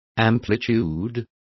Complete with pronunciation of the translation of amplitude.